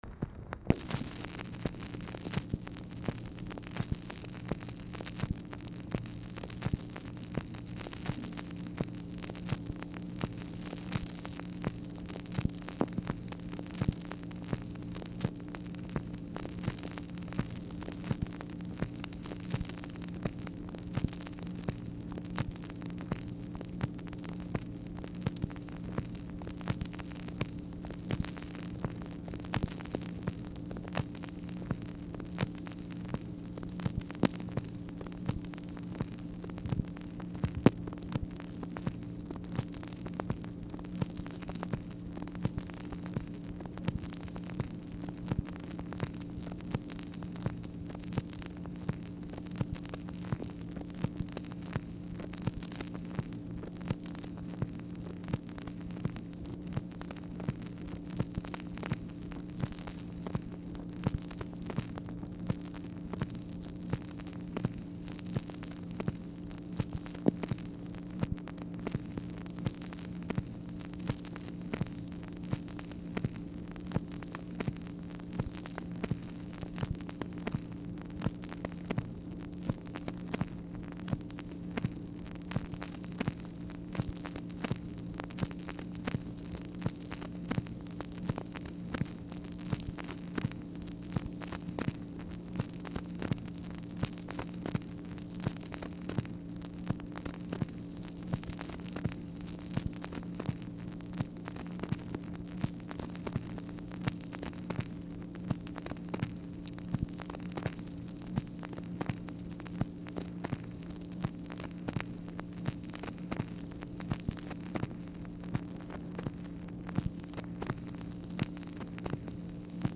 Format Dictation belt
White House Telephone Recordings and Transcripts Speaker 2 MACHINE NOISE